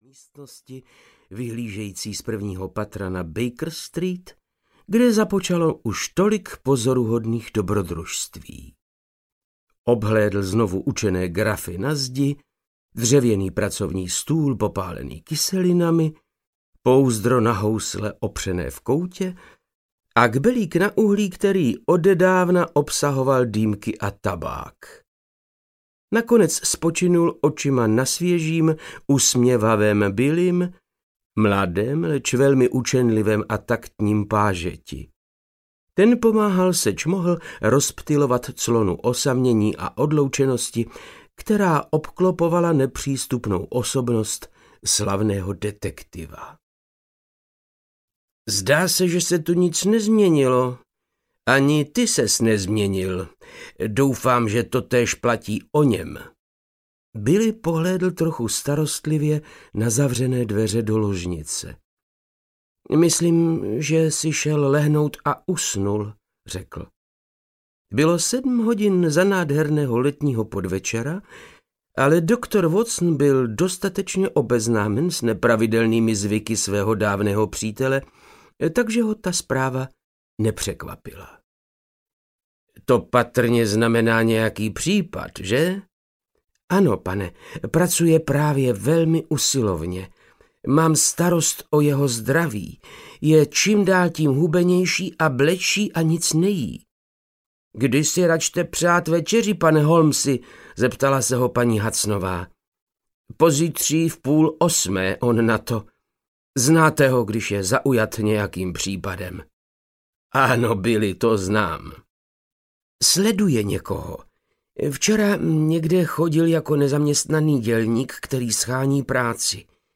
Mazarinův drahokam audiokniha
Ukázka z knihy
• InterpretVáclav Knop